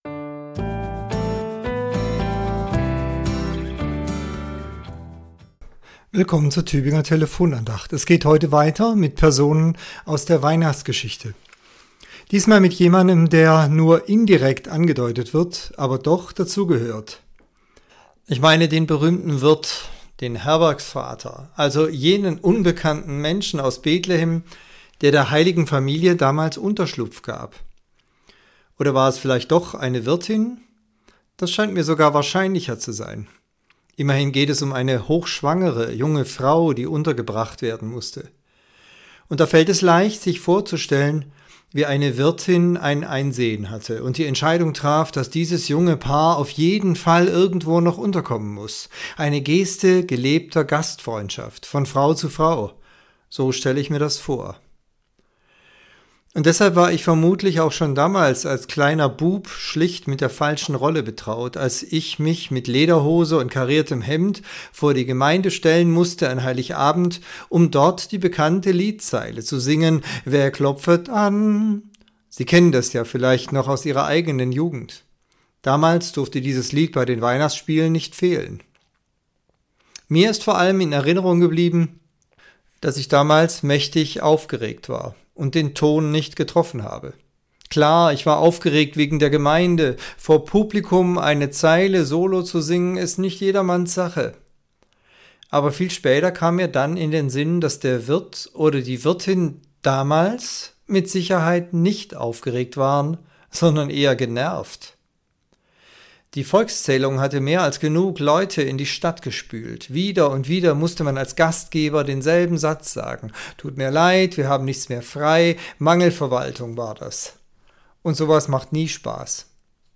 Andacht zur Weihnachtswoche Teil 2